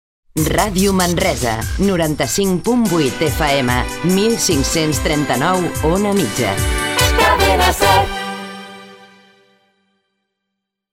Identificació i freqüències en OM i FM